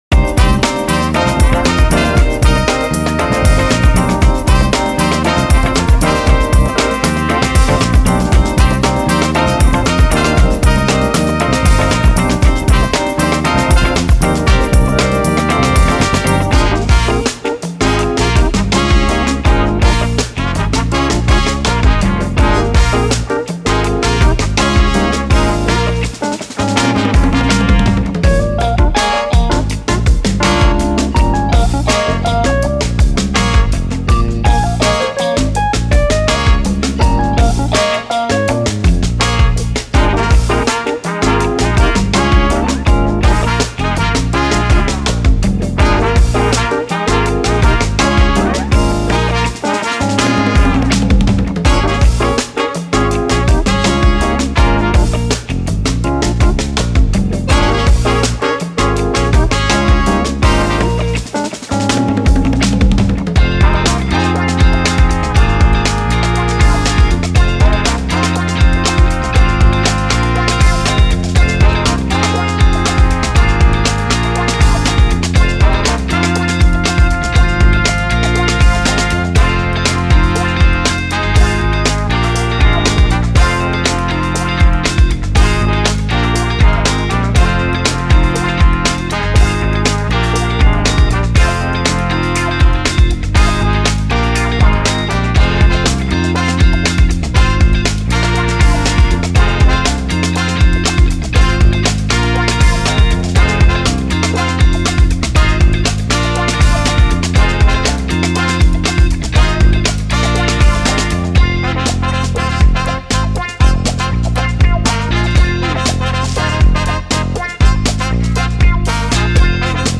Genre:Funk
キャッチーでソウルフル、そして一度聴いたら忘れられないブラスパートが満載です。
鋭くリズミカルなスタブがグルーヴを突き抜けます。
タイトなユニゾンラインが強力かつ正確に響きます。
スライディングのベンドやスクープが荒々しく表情豊かなキャラクターを加えます。
重要: デモで聴こえるその他の楽器はすべてプレビュー用です。
Live Performed & Played